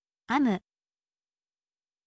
amu